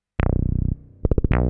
hous-tec / 160bpm / bass
tb303b-6.mp3